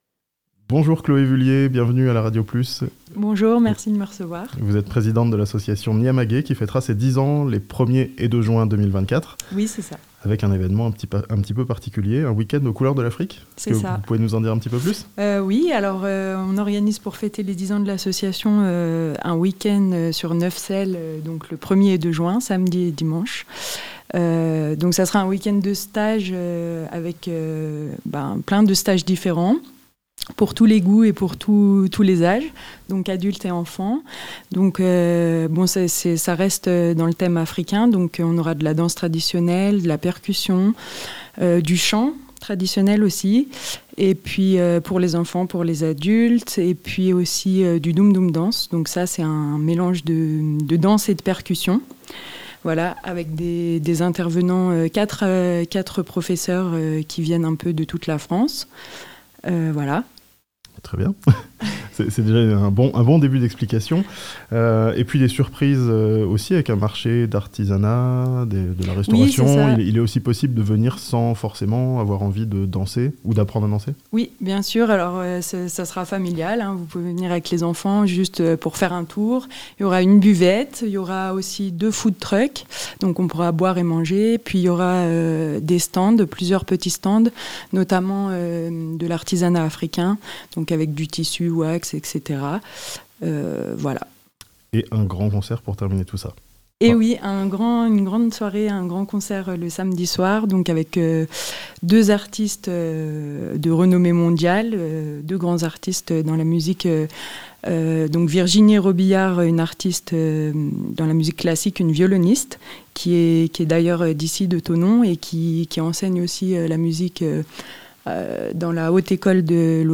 L'association Nyamaguè fêtera ses 10 ans les 1er et 2 juin, à Neuvecelle (interview)